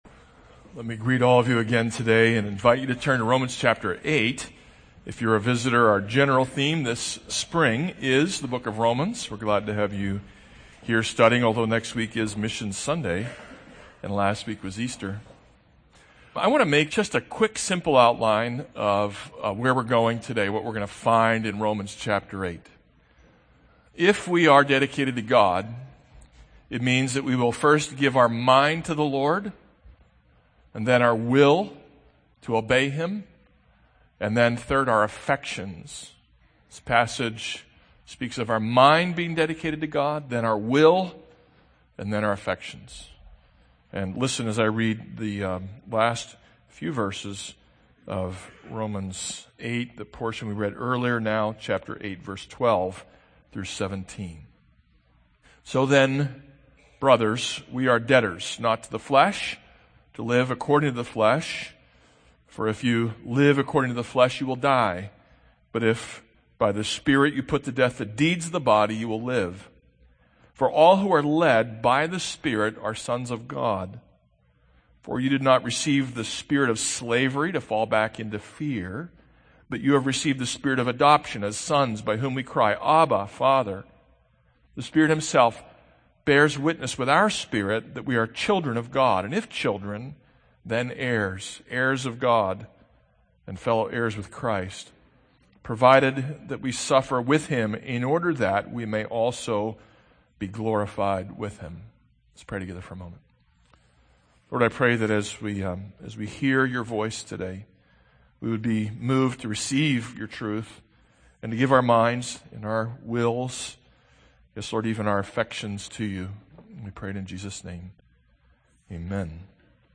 This is a sermon on Romans 8:5-17.